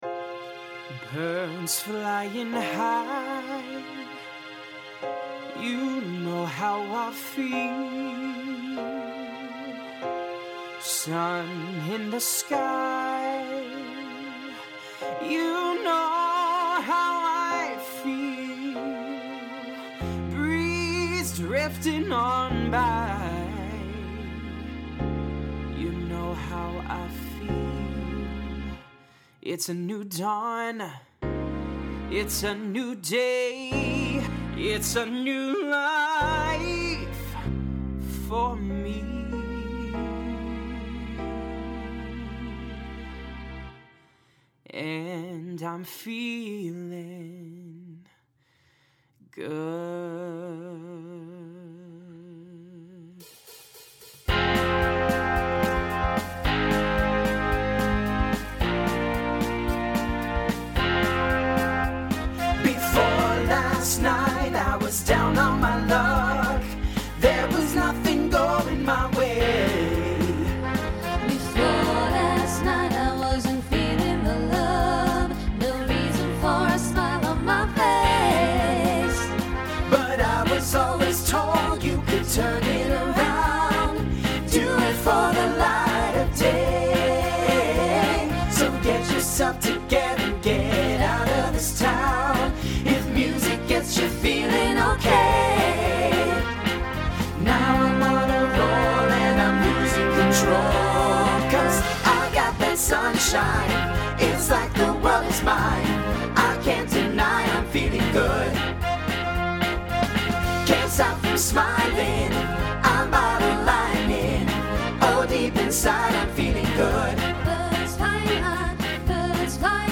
Mid-tempo